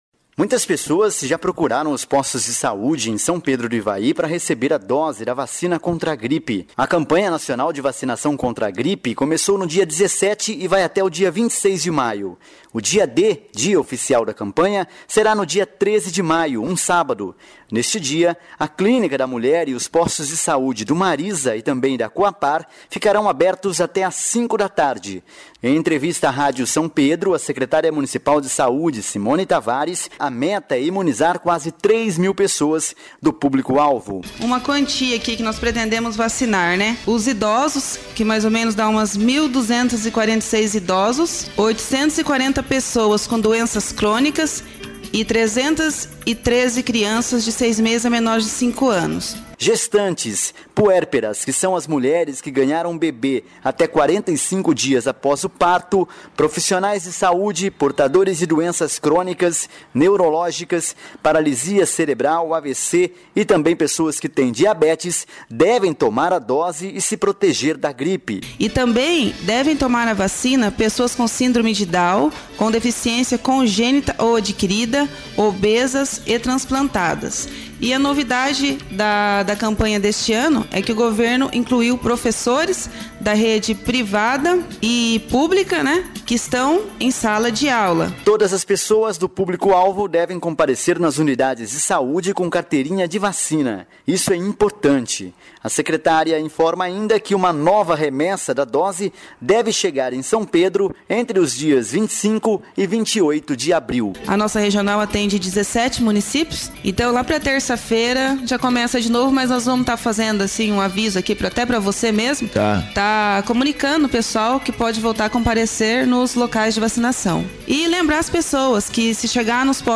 Entrevista da secret�ria de Sa�de sobre a Campanha Nacional de Vacina��o contra a Gripe